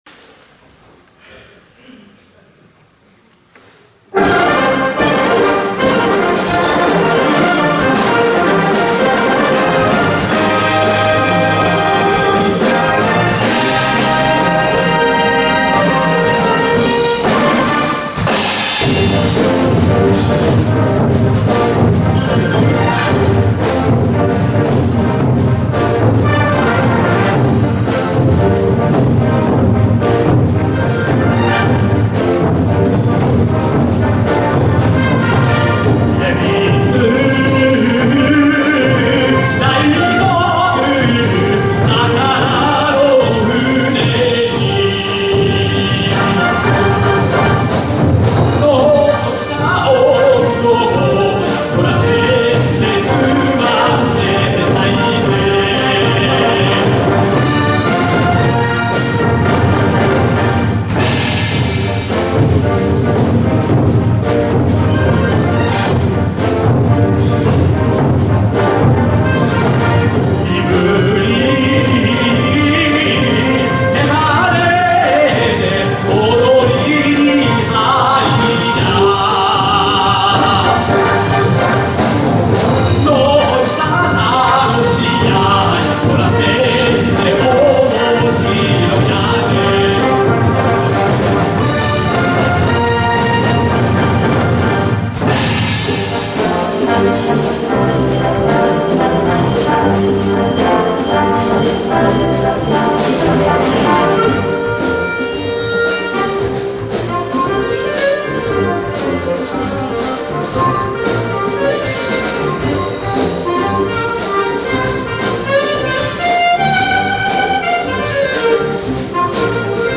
歌唱版
2015年10月25日 東広島市黒瀬生涯学習センターせせらぎホールにて